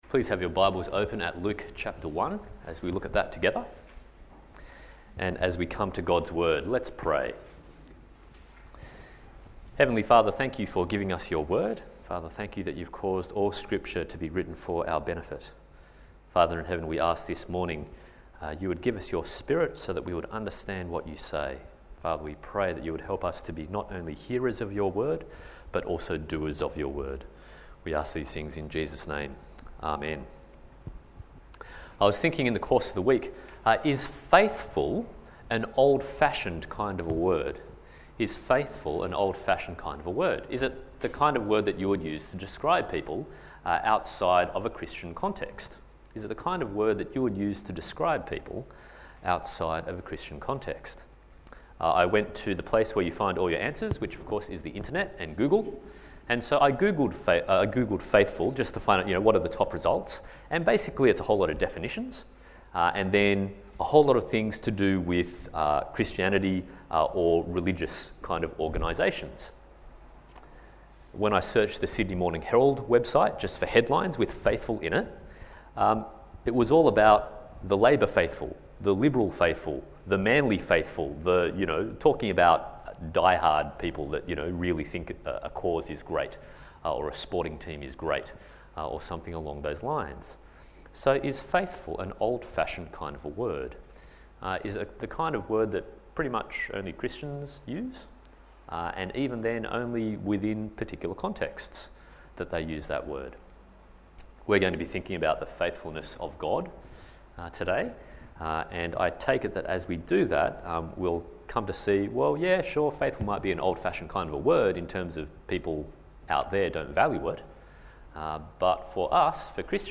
Isaiah 40:1-11 Service Type: Sunday Morning « The God of Great Reversals Angels